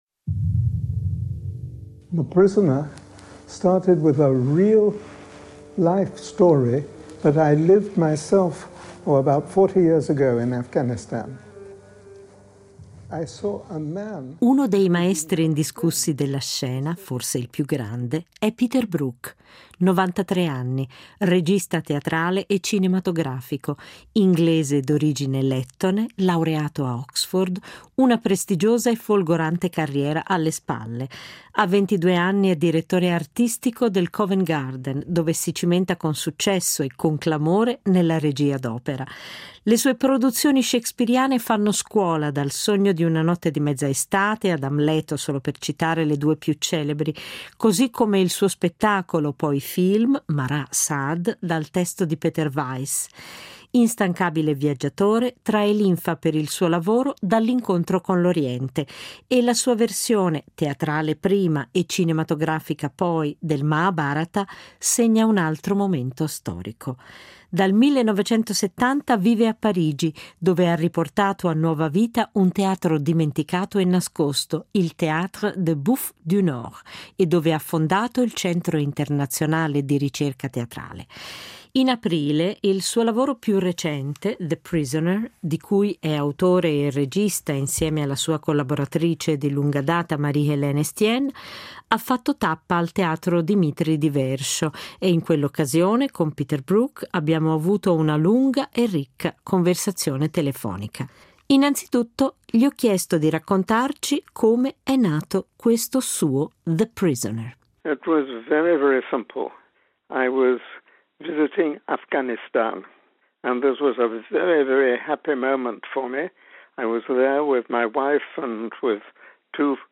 In quell'occasione è stato possibile avere con lui una lunga conversazione telefonica.